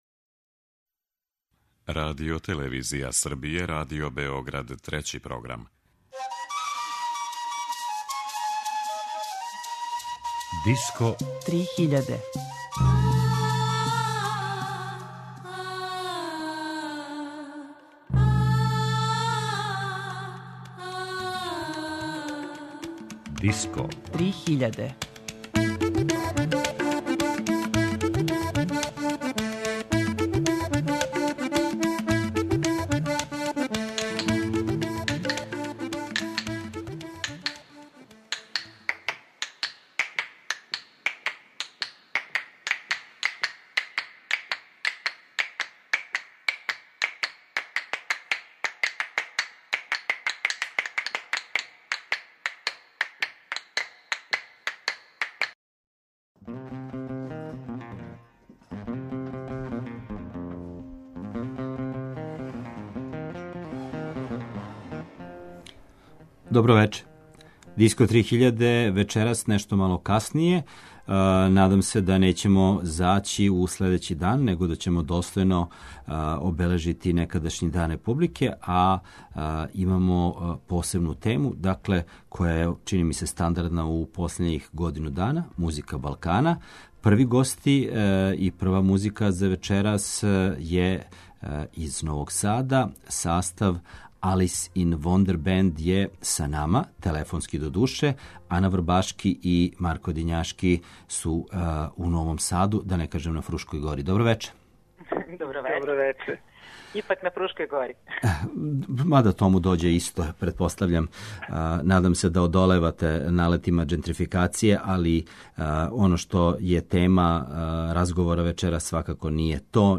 govoriće uživo u emisiji o svom radu, iskustvima iz prošlosti, kao i o budućim planovima ovog benda.